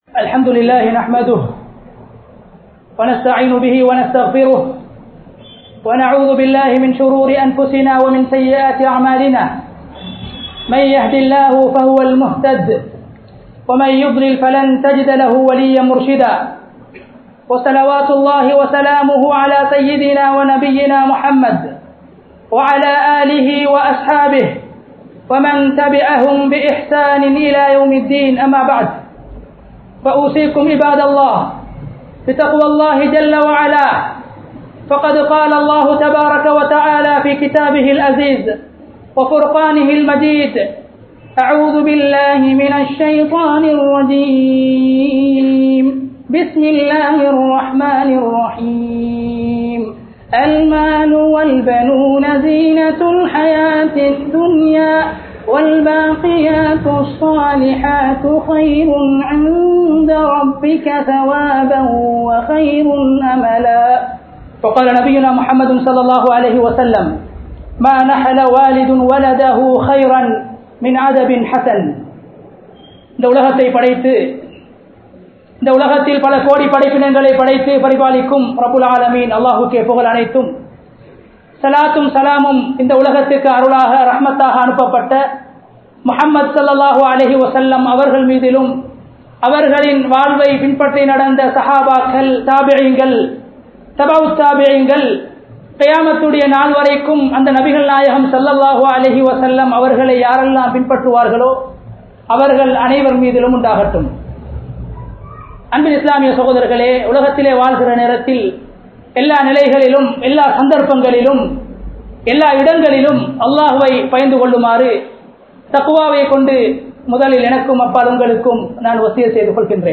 Kulanthaien Seer Thiruththam Thaaien Kaiel (குழந்தையின் சீர் திருத்தம் தாயின் கையில்) | Audio Bayans | All Ceylon Muslim Youth Community | Addalaichenai